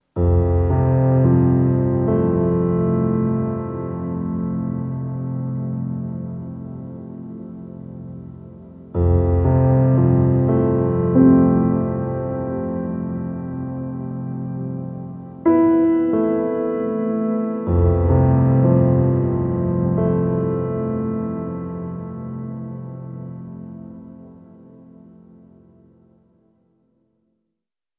Treated Piano 05.wav